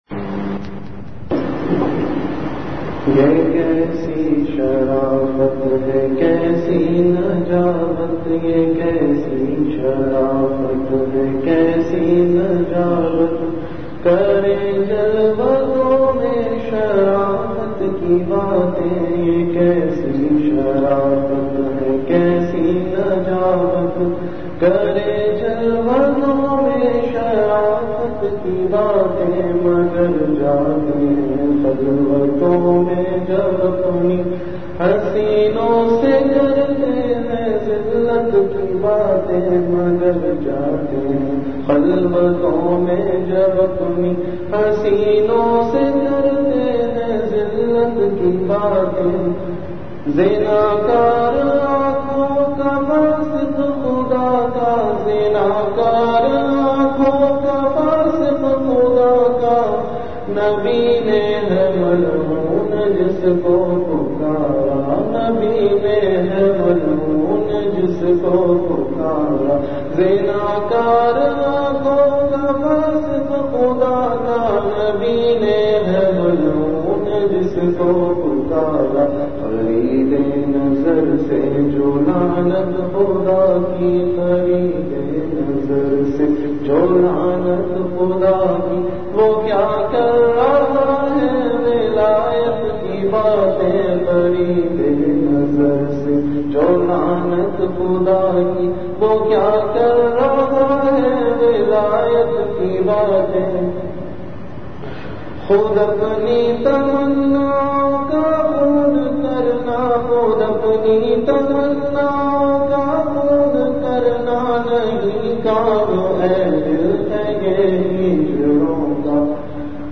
Delivered at Home.
Majlis-e-Zikr